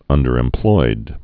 (ŭndər-ĕm-ploid)